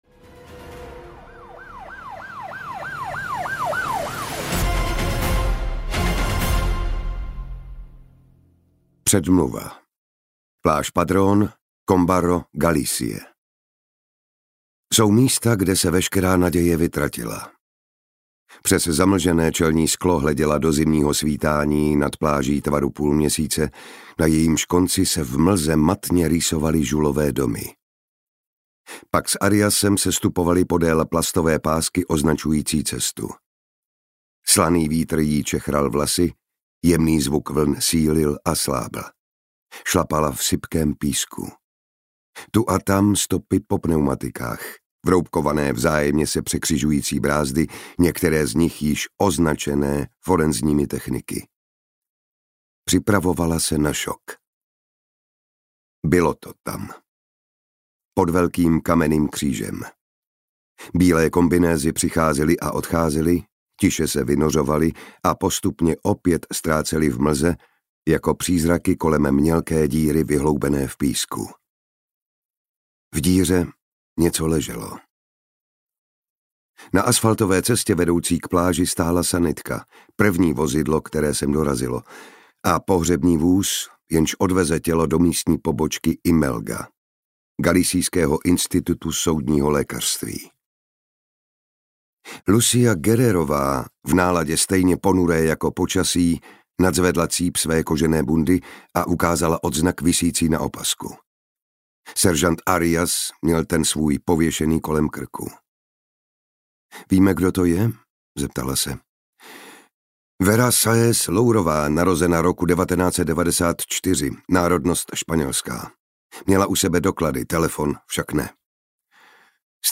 Nenávist audiokniha
Audiokniha Nenávist, kterou napsal Bernard Minier. Neznámý zabiják v Galicii vraždí pracující ženy, které brzy ráno pospíchají do zaměstnání.